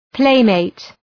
Προφορά
{‘pleımeıt}